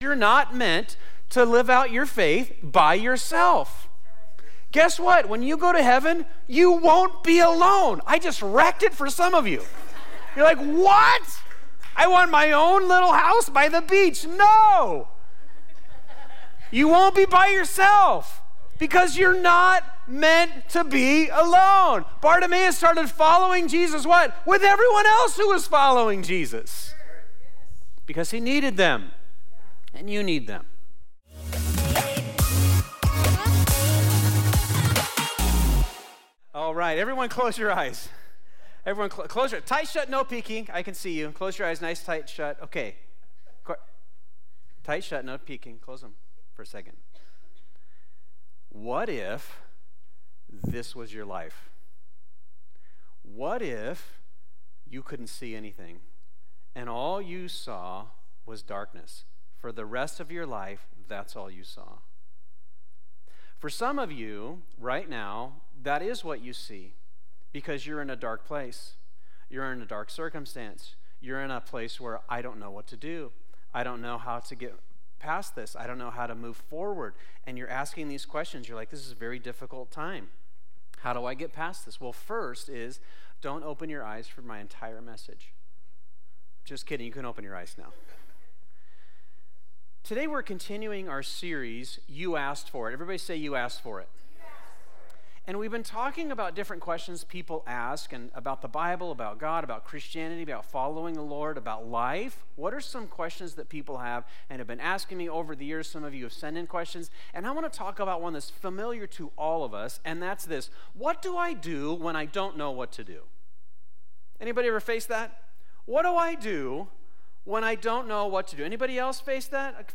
Confusion Direction Sunday Morning This is Part 6 of "You Asked For It," our sermon series at Fusion Christian Church where we answer real questions from members of our church community.